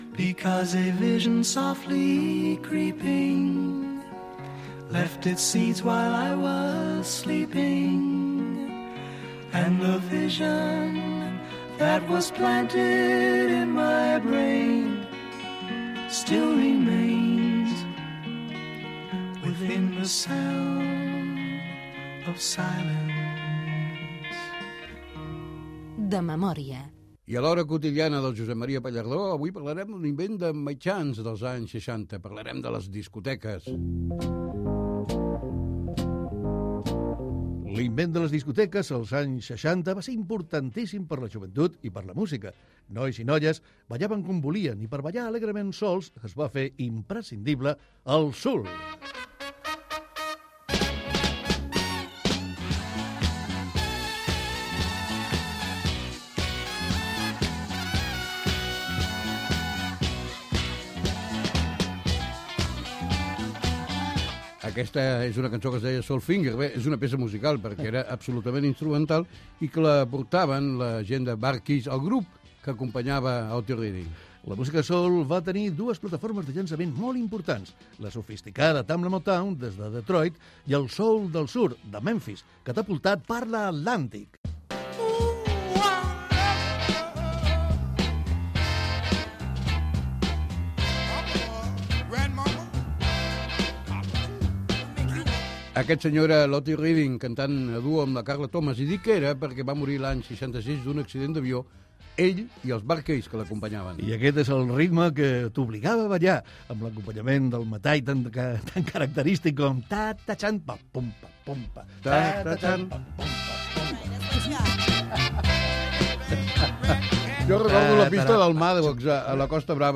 Indicatiu del programa secció "L'hora quotidiana", amb l'inici de les discoteques i temes de la música "soul"
Entreteniment